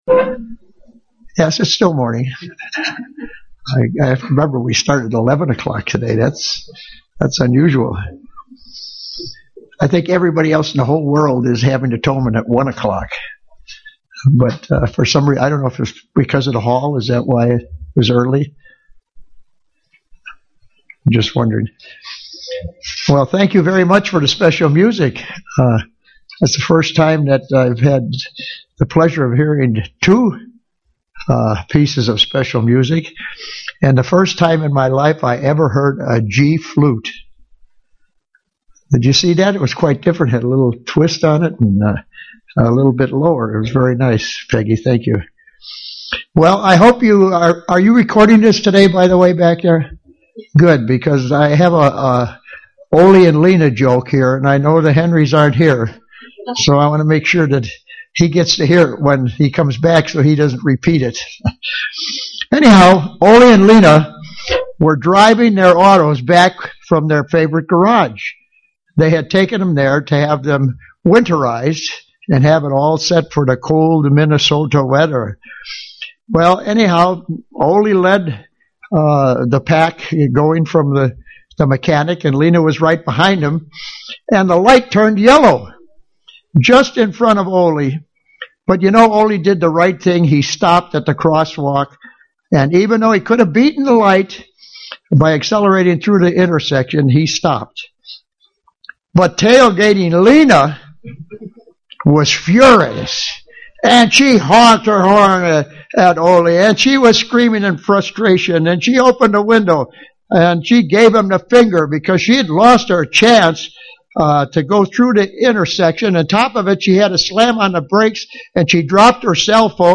Given in La Crosse, WI
Print Remove Satan from our lives UCG Sermon Studying the bible?